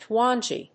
/twˈæŋi(米国英語), ˈtwɑ:ndʒi:(英国英語)/